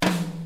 Southside Percussion (15).wav